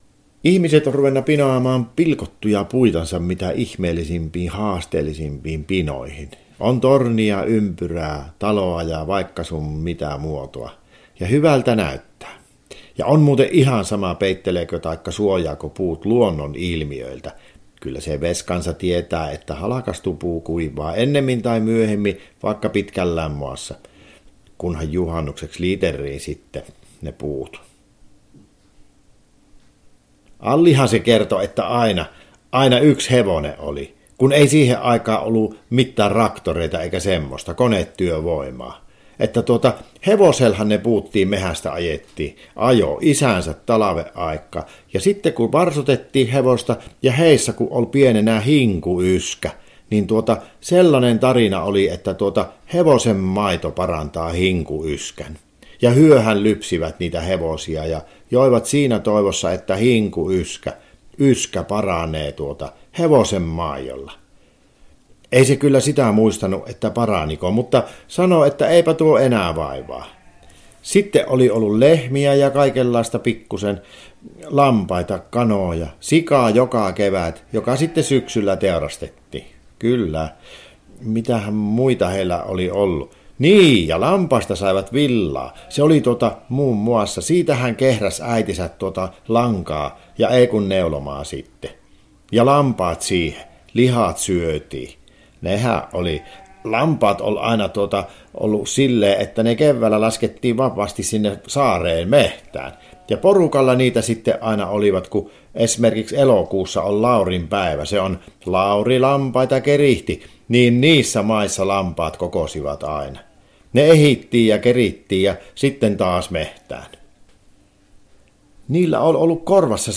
Puhujana näyttelijä